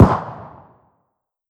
CookoffSounds / shotshell / far_2.wav
Cookoff - Improve ammo detonation sounds
far_2.wav